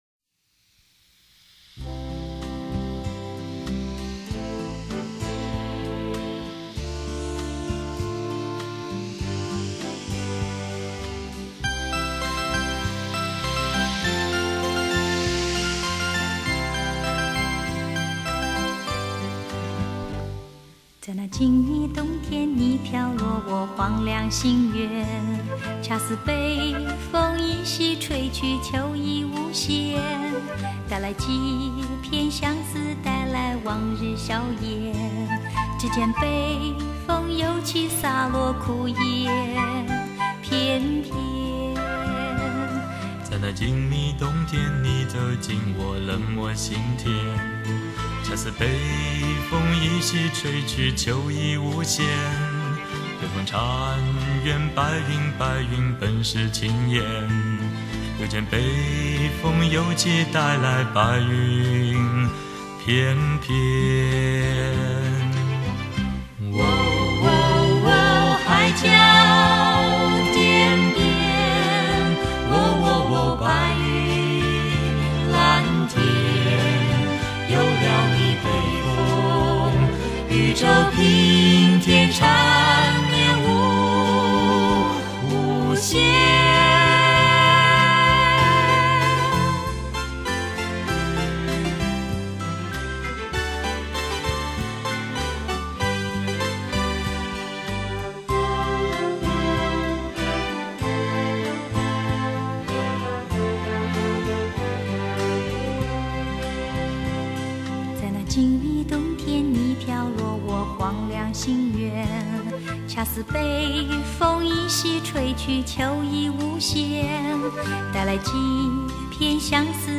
八张黄金版CD之100首曲目，全以最新24位元数位录音技术处理，重现当年歌手原声重唱，音色更为甘亮饱满。